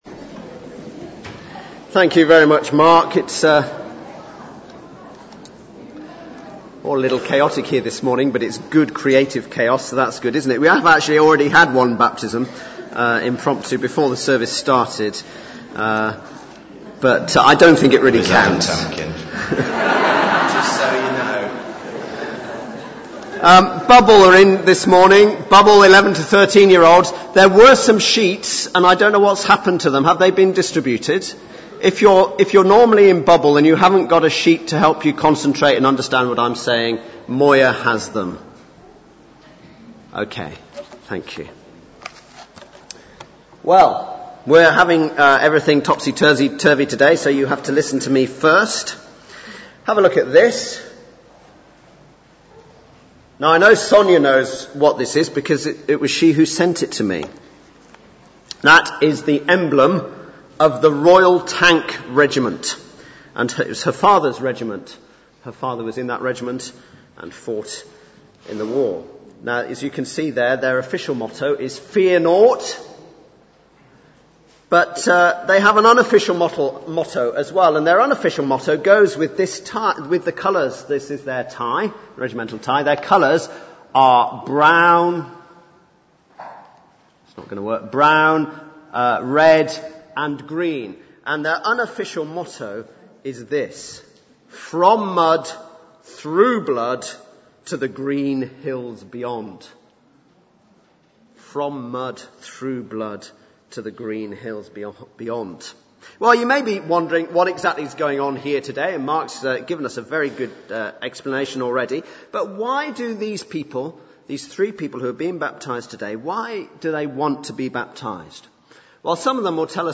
Baptismal Service – September 2010